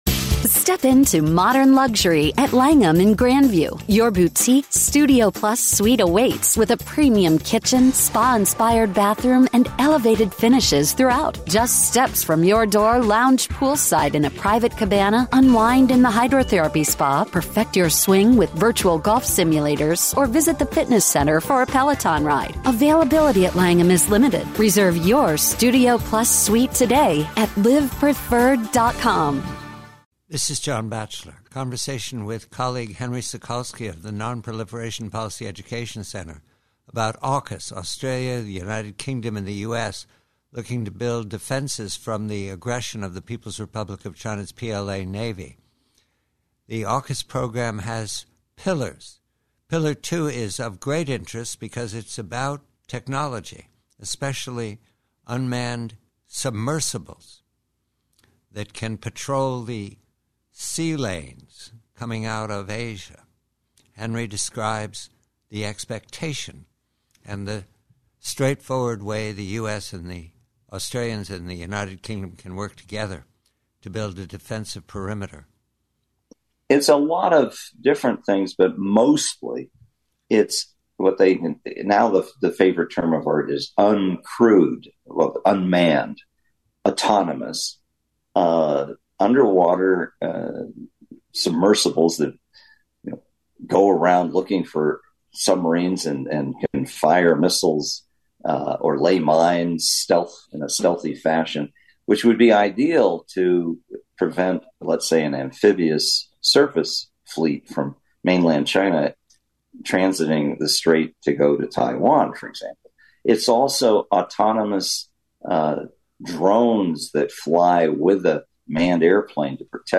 Conversation excerpt